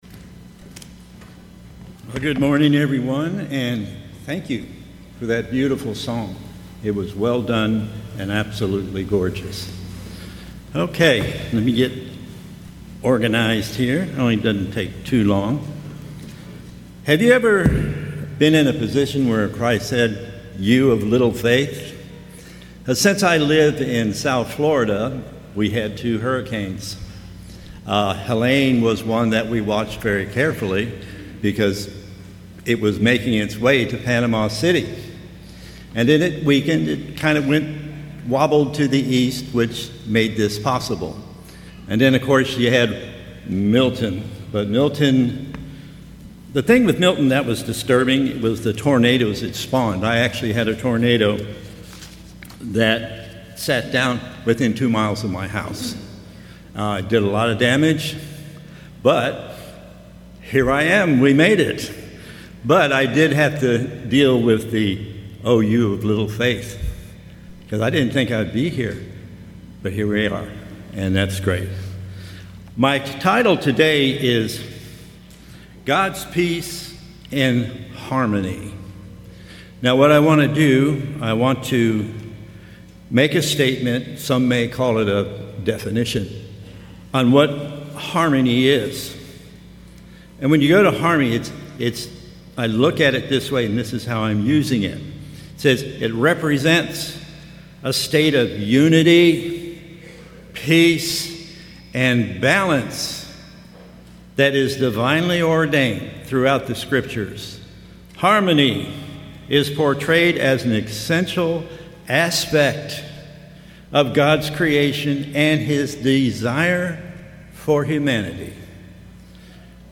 This message was given during the 2024 Feast of Tabernacles in Panama City Beach, Florida.